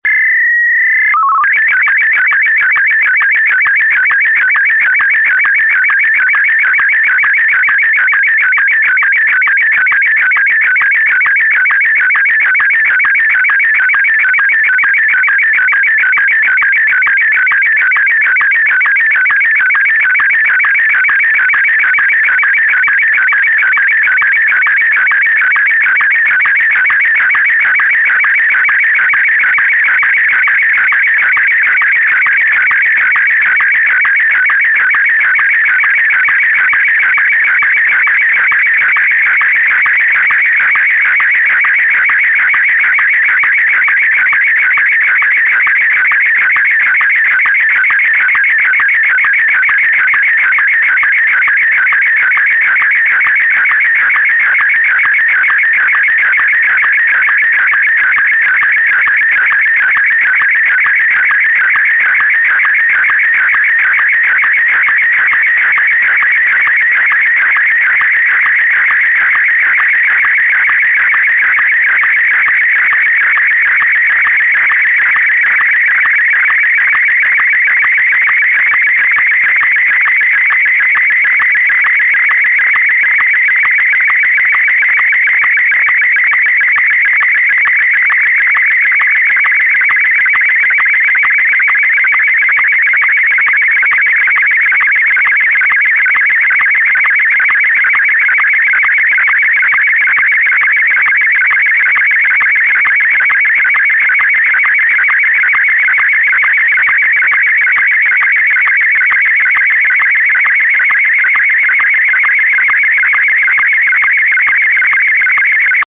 Globo 4: Modos en SSTV - Imagen de prueba
Martin M1